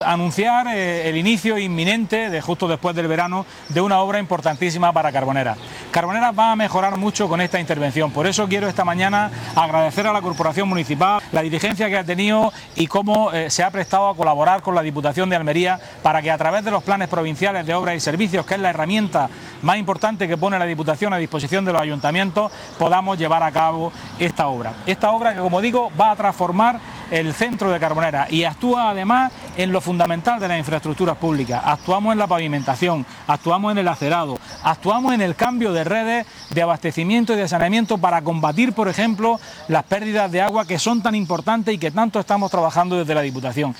02-06_carboneras_obras_diputado.mp3